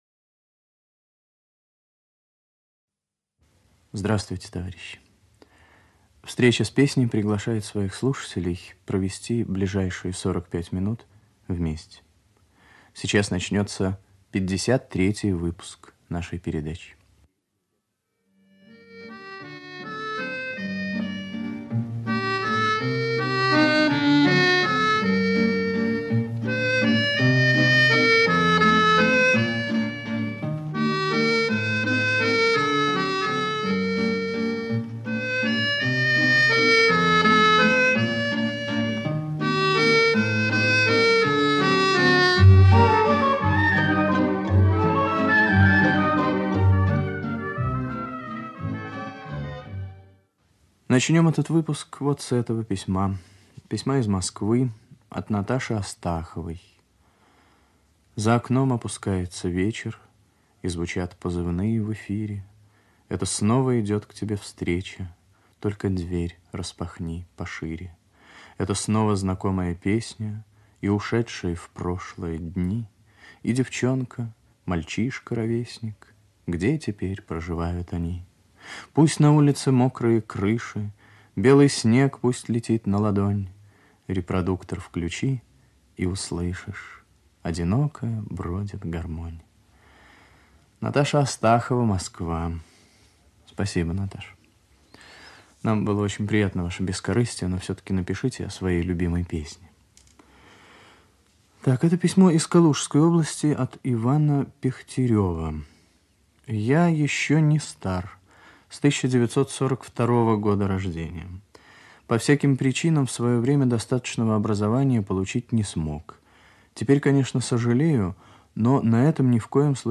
Революционная песня
Оркестр 2.
Хор и Оркестр 4.